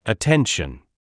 Attention.wav